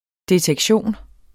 Udtale [ detεgˈɕoˀn ]